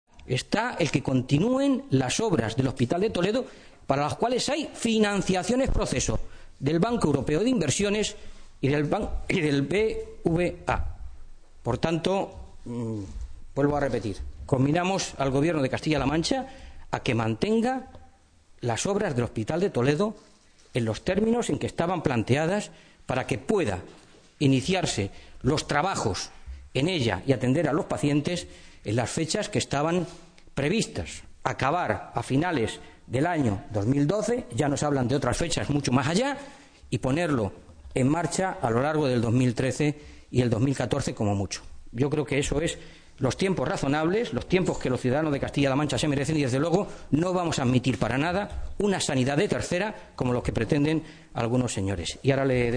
El diputado regional y portavoz socialista de la Comisión de Sanidad de las Cortes regionales, Fernando Mora, exigía hoy al Gobierno de Castilla-La Mancha que mantengan las obras del hospital de Toledo en los plazos y términos previstos.
Cortes de audio de la rueda de prensa